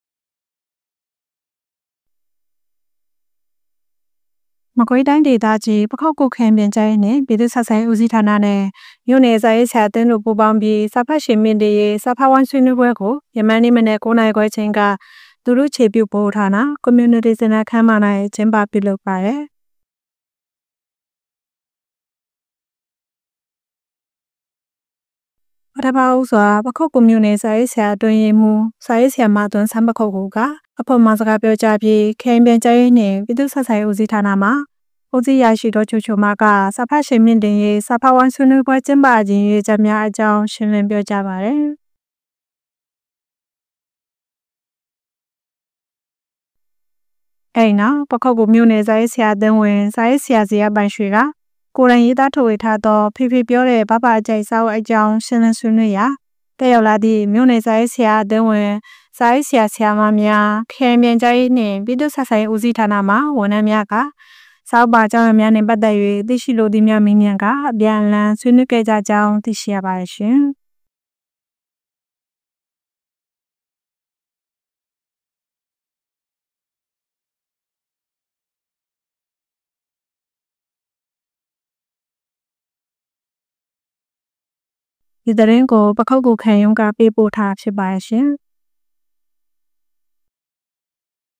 ပခုက္ကူမြို့၊ လူထုအခြေပြုဗဟိုဌာန၌ စာဖတ်ရှိန်မြှင့်တင်ရေးစာဖတ်ဝိုင်းဆွေးနွေးပွဲပြုလုပ်